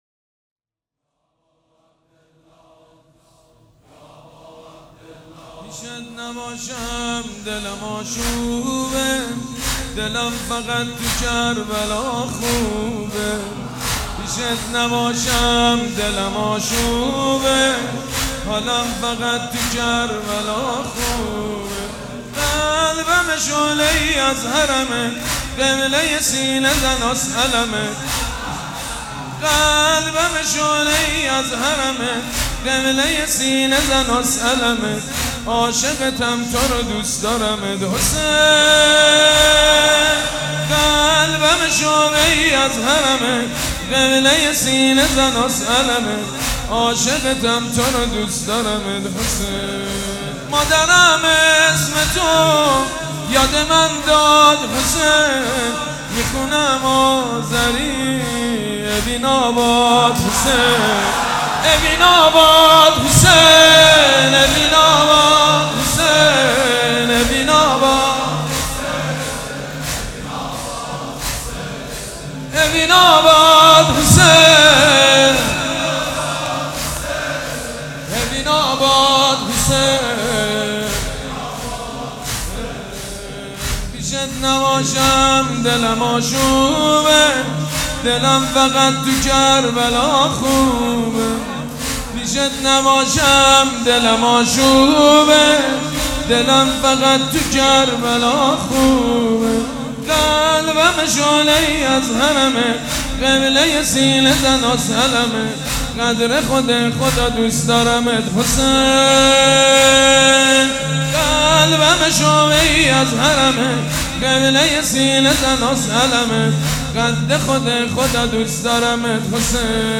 شب اول محرم - به نام نامی حضرت مسلم(ع)
سید مجید بنی فاطمه